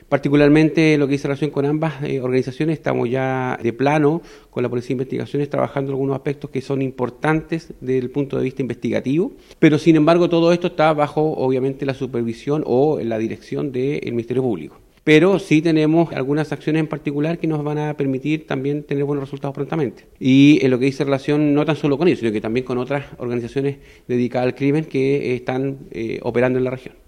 El jefe de la Décimo Cuarta Zona de Carabineros, general Patricio Faunes, confirmó acciones particulares y en conjunto con la PDI para obtener resultados.